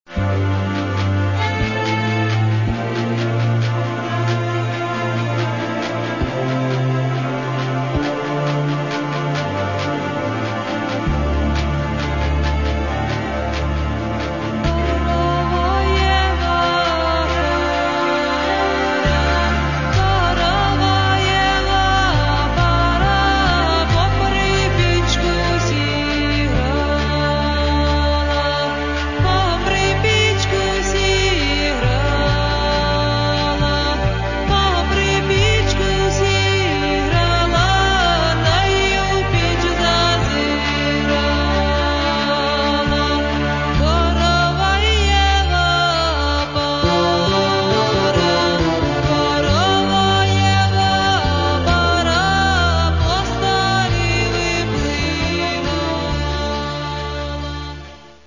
в прозрачных, нежных, женственных его проявлениях